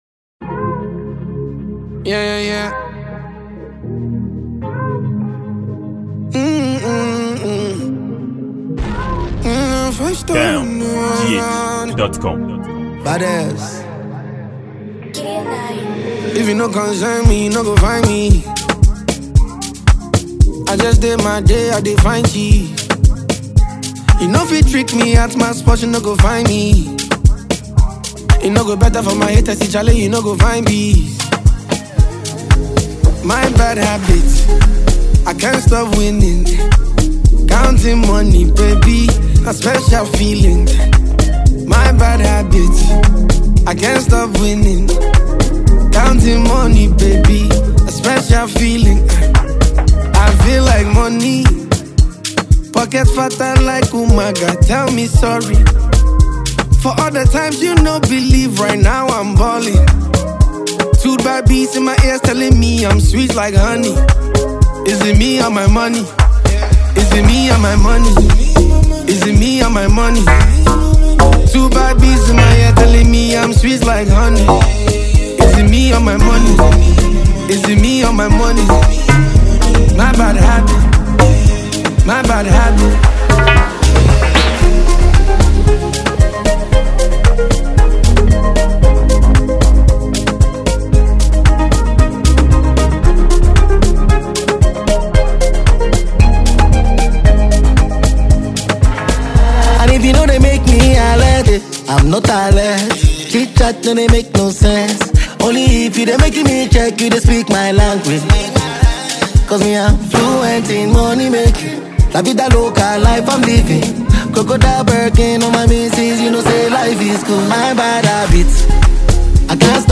a Ghanaian afrobeats singer